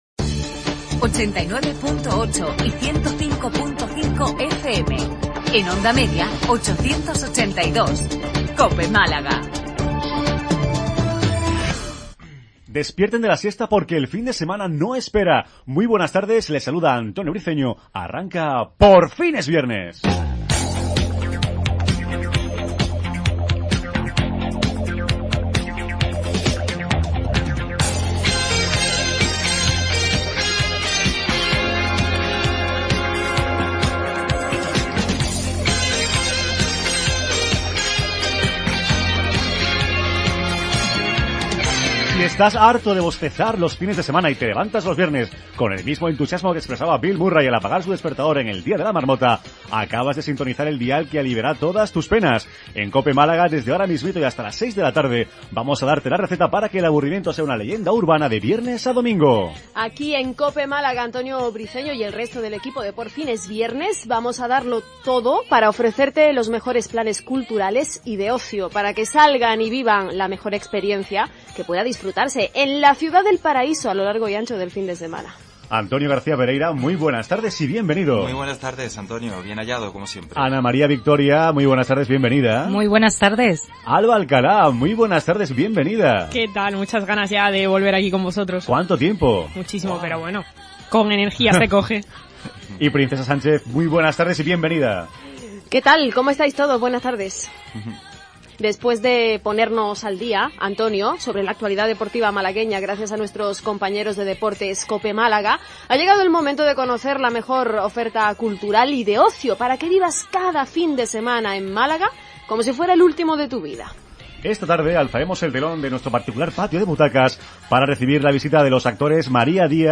AUDIO: Programa de radio cultural y de ocio dirigido a todo tipo de público malagueño